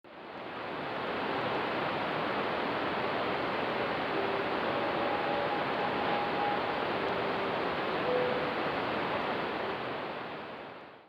Interestingly, the slower and sometimes larger the fireball, the less
radio scatter reflection it creates. This one made just a subtle
"twinkle."